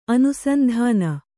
♪ anusandhāna